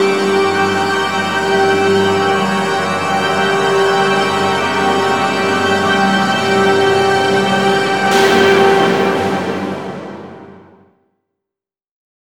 36aa01pad-gm.wav